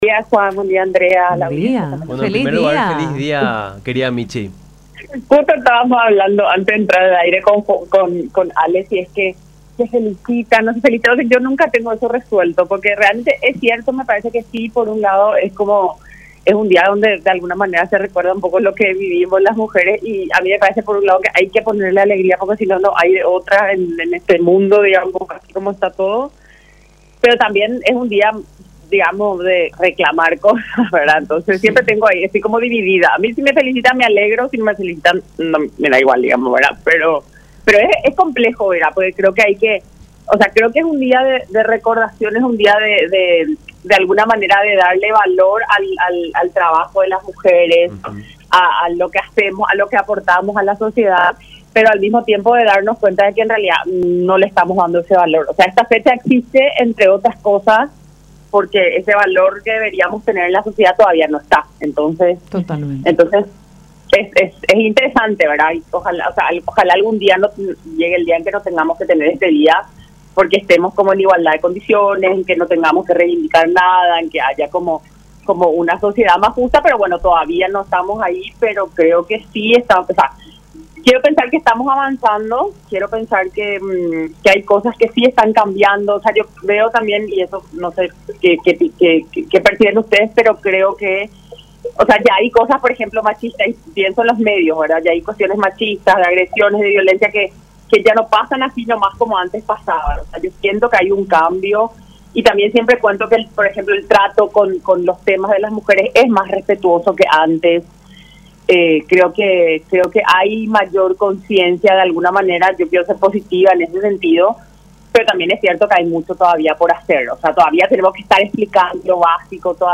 en conversación con Nuestra Mañana por La Unión